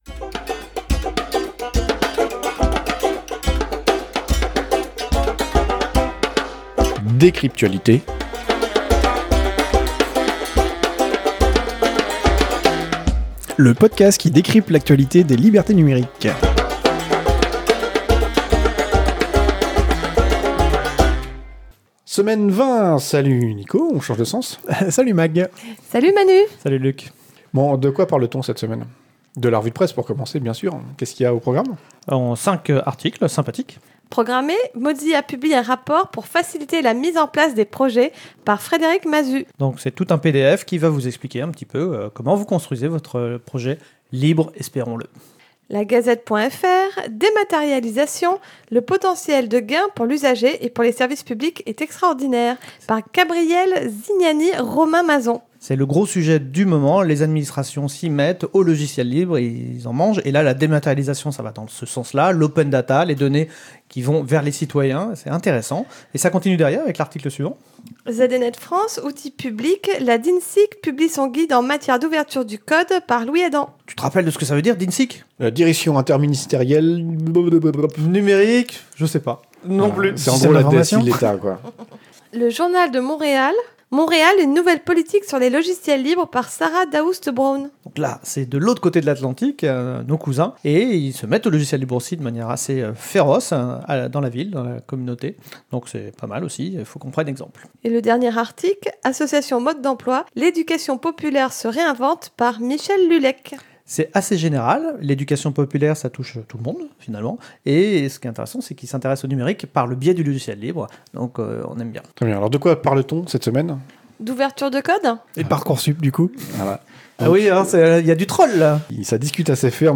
Lieu : April - Studio d'enregistrement
Revue de presse pour la semaine 20 de l'année 2018